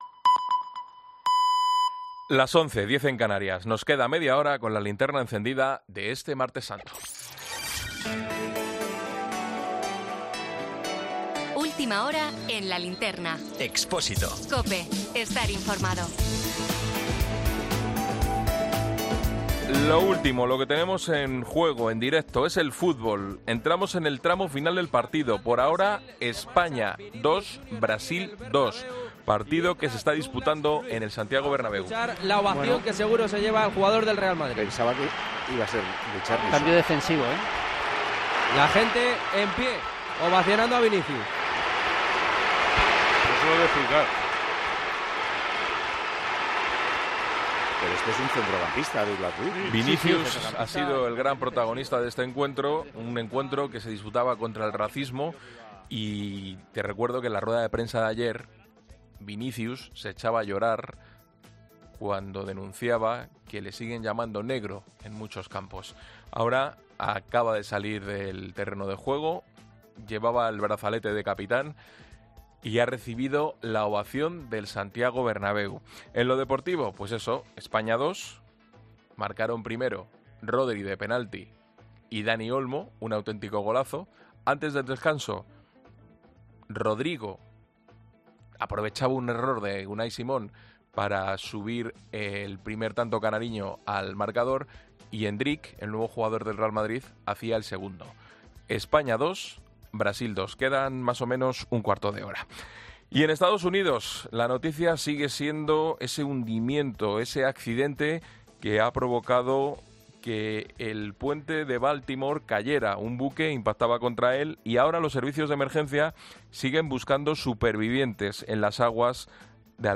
Boletín 23.00 horas del 26 de marzo de 2024 La Linterna